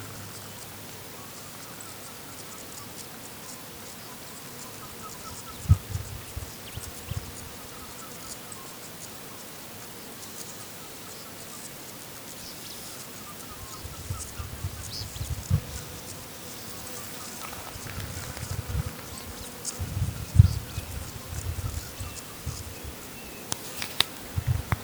Nome científico: Chunga burmeisteri
Nome em Inglês: Black-legged Seriema
Localidade ou área protegida: Área Natural Protegida Quebracho de la Legua
Condição: Selvagem
Certeza: Gravado Vocal